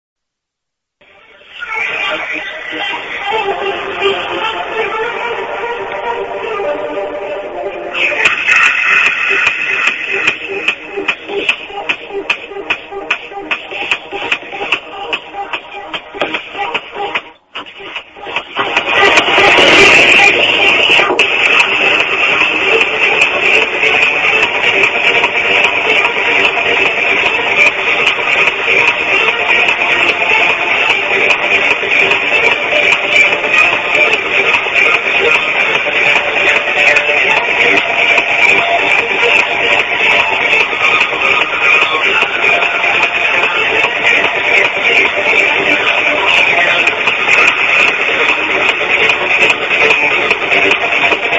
Question unknown hardtrance (sample inside)